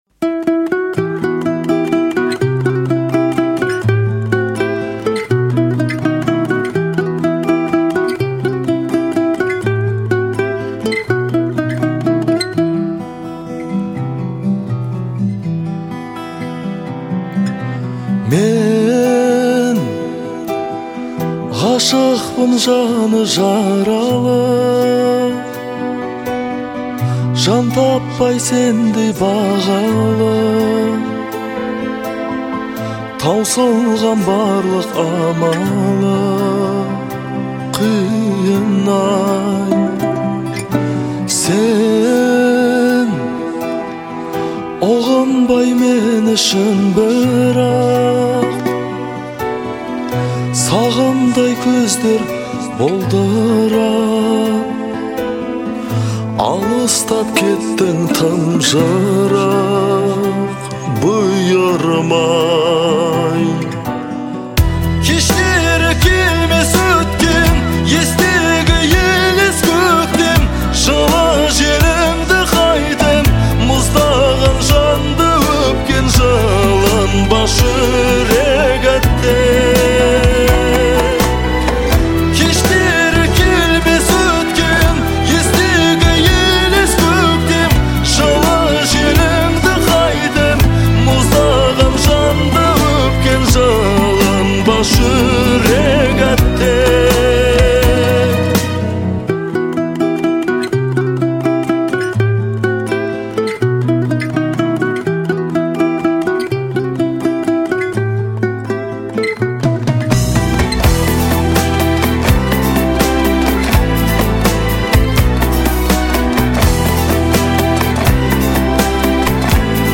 относящаяся к жанру казахской поп-музыки.